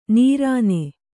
♪ nīrāne